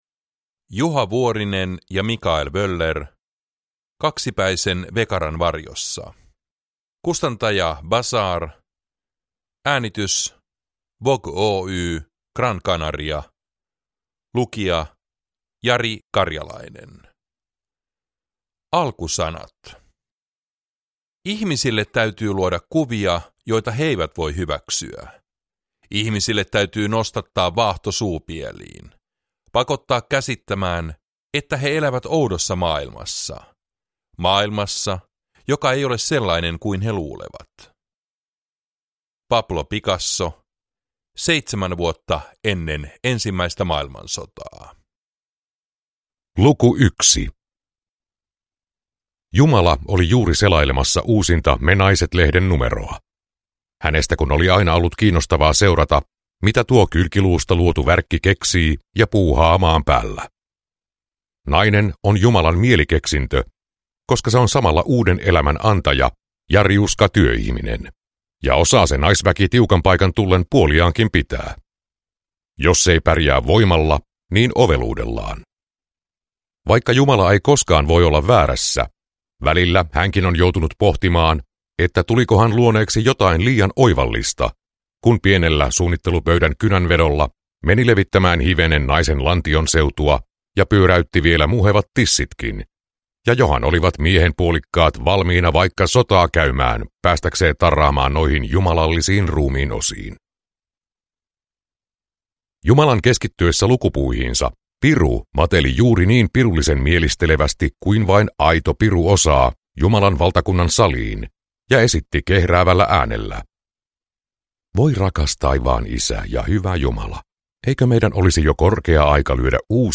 Kaksipäisen vekaran varjossa – Ljudbok